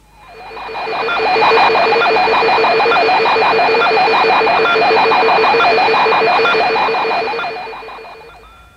This recording of the transmission of Luna-9 after its soft landing on the lunar surface was received by Jodrell-Bank-Radioobservatory in UK with its 76m dish.
luna-9-landet_weich_auf-der-mondoberflaeche-auf-und-sendet-bild.mp3